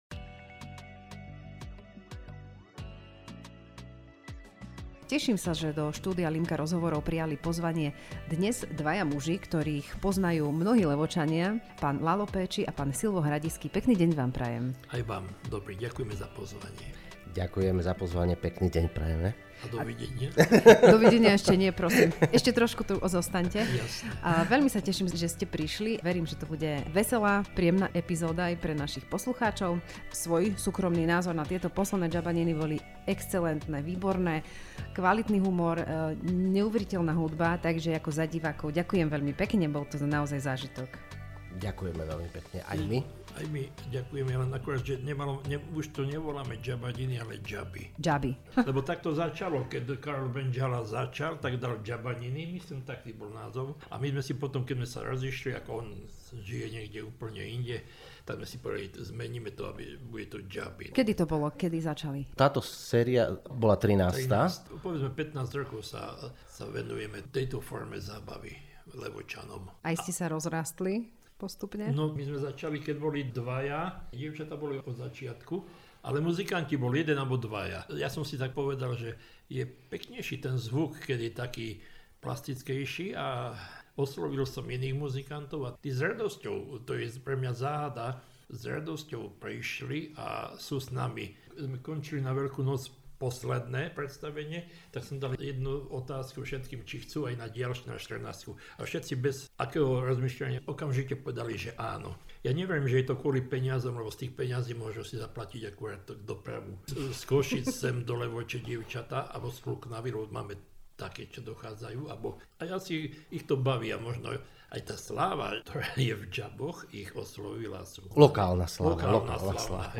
LIMKA – rozhovory